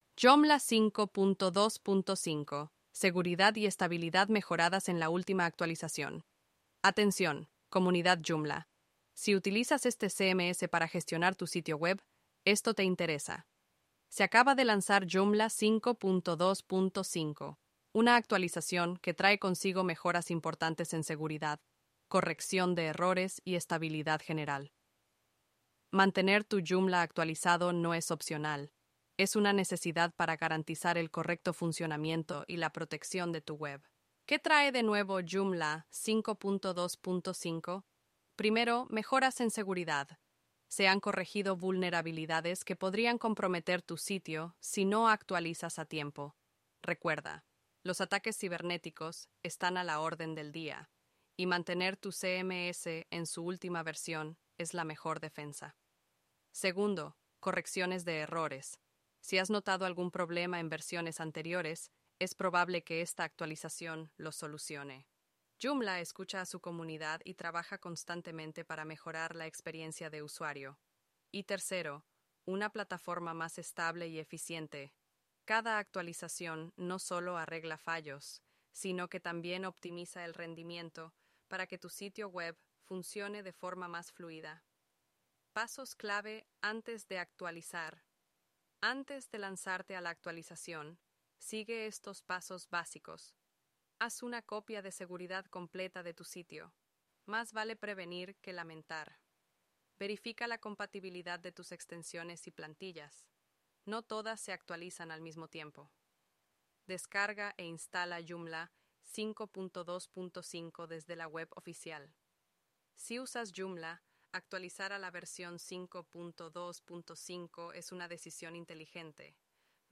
Podcast sobre Joomla en español con JoomlIA Robers, una IA entrenada